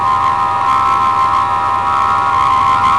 saucer2.wav